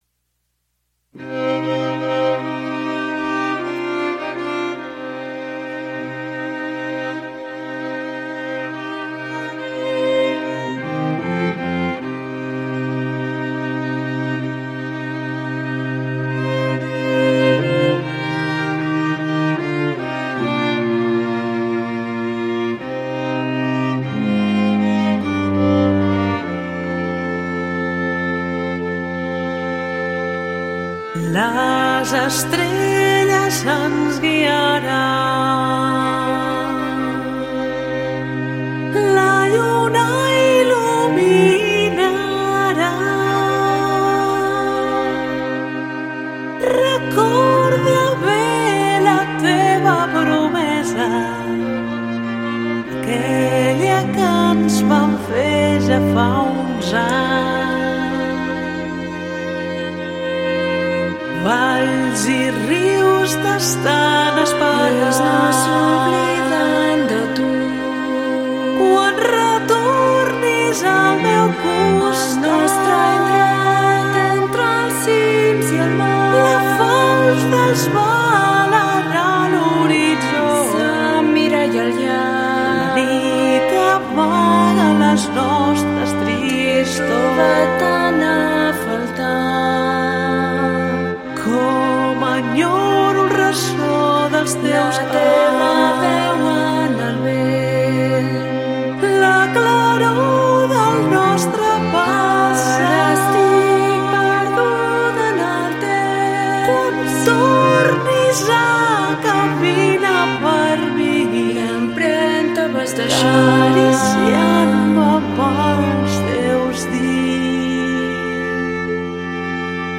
(Solo de corda i flauta)